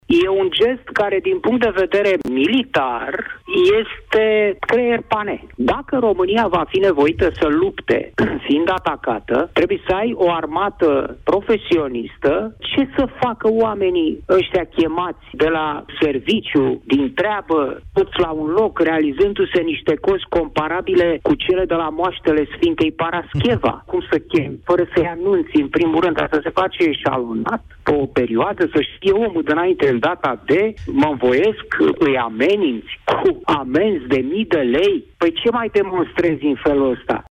Adrese greșite pentru mobilizarea rezerviștilor din Capitală și județul Ilfov și probleme la exercițiul Mobex 2025. Înștiințarea pentru prezentarea la unitate trebuia făcută din timp, și nu cu amenințări, spune la Europa FM scriitorul și gazetarul Cristian Tudor Popescu, care atacat „bâlbâiala” MApN.